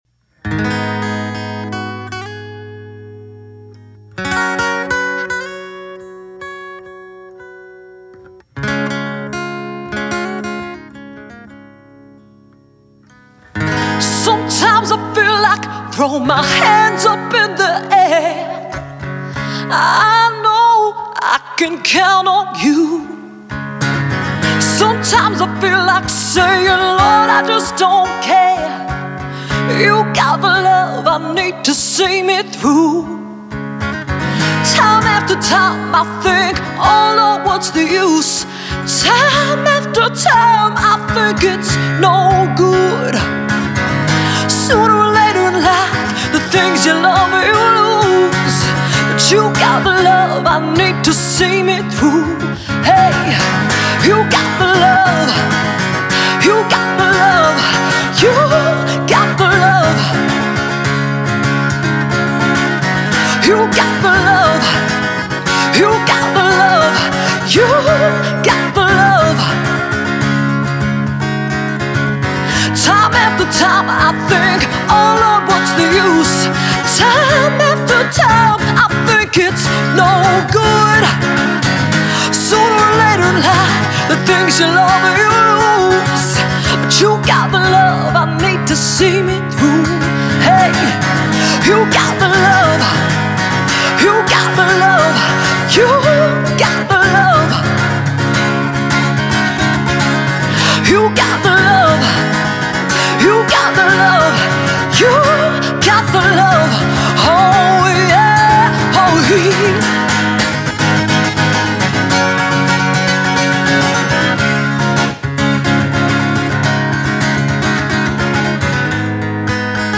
vocalist
DJ & percussionist